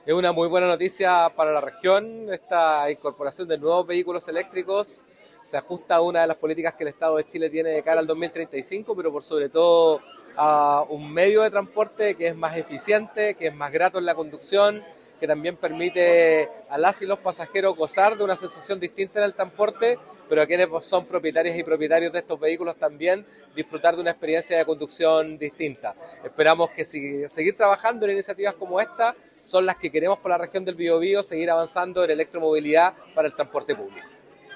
Desde el ámbito del transporte público menor, el seremi de Transportes, Patricio Fierro, destacó los beneficios que trae esta iniciativa, orientada a renovar la flota de vehículos con un enfoque en eficiencia energética y sustentabilidad.